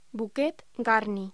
Locución